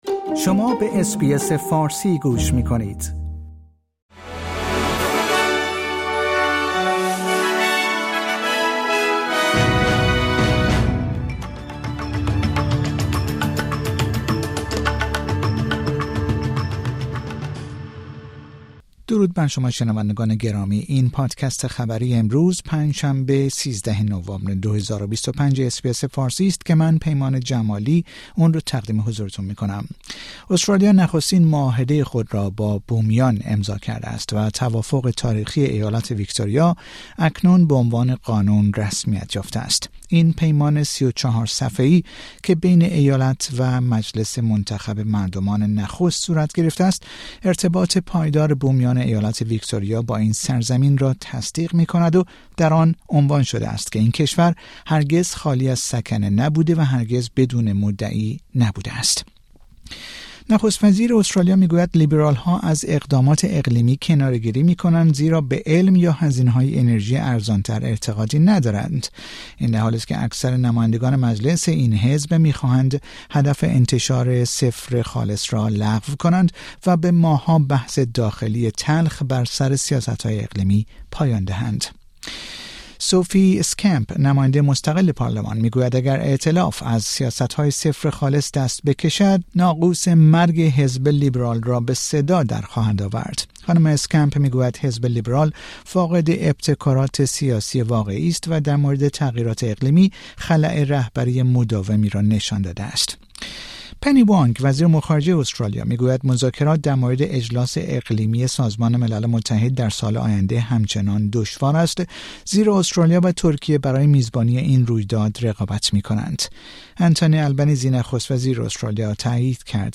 در این پادکست خبری مهمترین اخبار روز پنج شنبه ۱۳ نوامبر ارائه شده است.